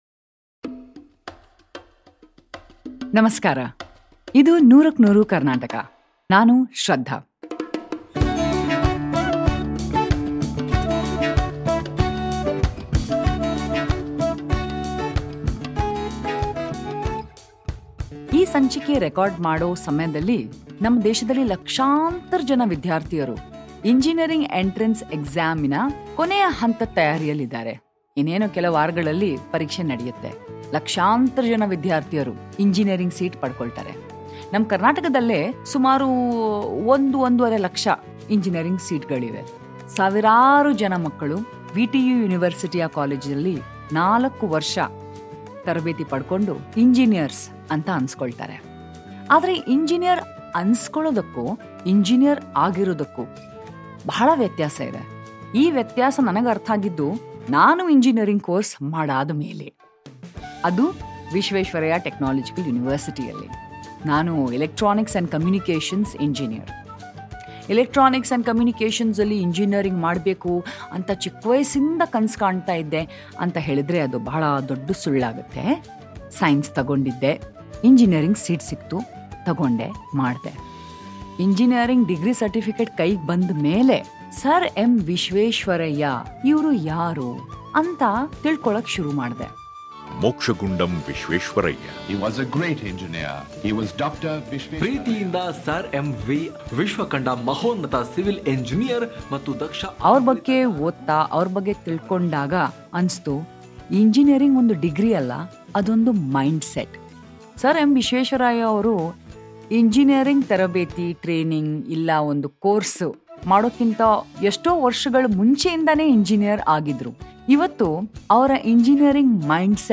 featuring various guests, this series of enlightening conversations on the culture and values of Karnataka